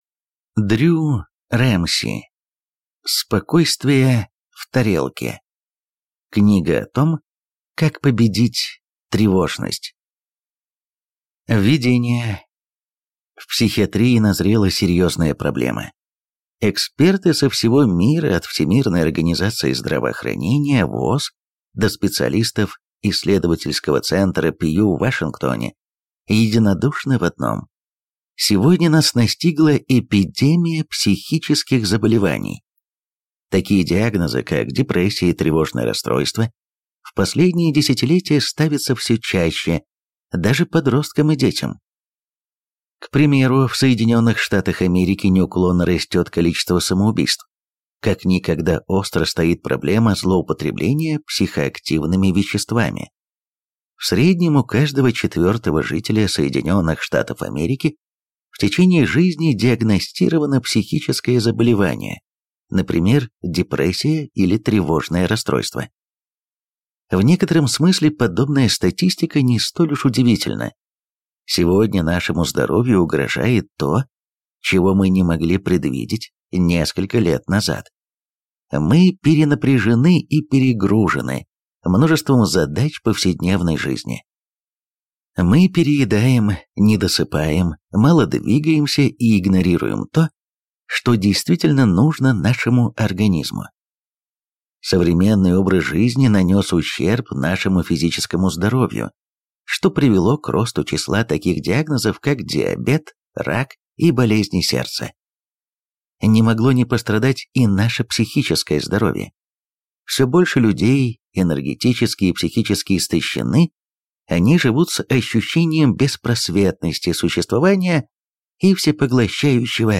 Аудиокнига Спокойствие в тарелке. Книга о том, как победить тревожность | Библиотека аудиокниг